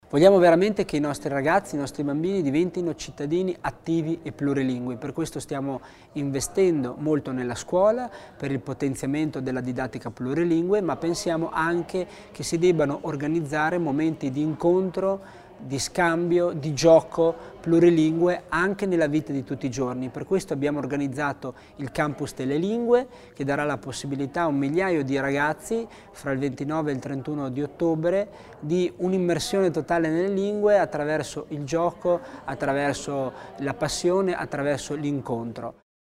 L'Assessore Tommasini spiega il valore del campus delle lingue